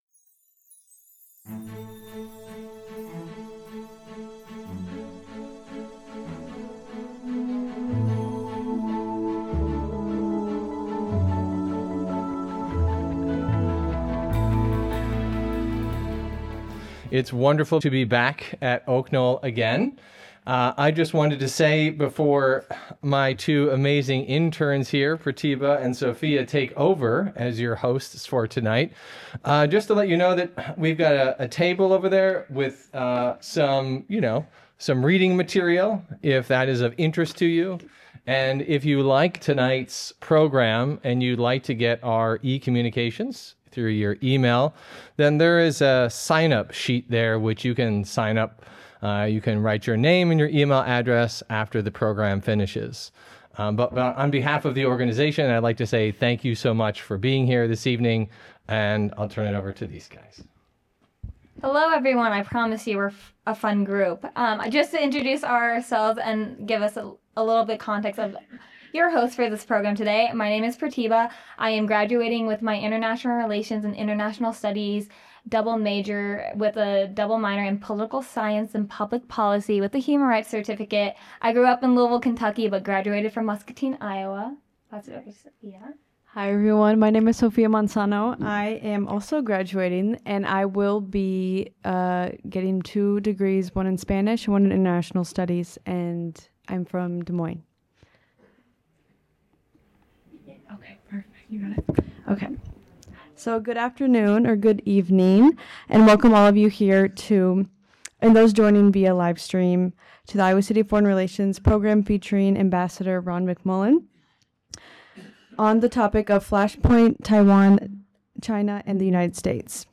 This presentation examines the underlying interests of the United States, China, and Taiwan; the potential pathways to conflict; and the far-reaching consequences for global security, diplomacy, and technology. Featured speaker: Ron McMullen Ambassador Ronald McMullen is a career diplomat with over 30 years of service as a U.S. Foreign Service Officer.